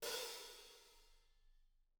R_B Hi-Hat 08 - Room.wav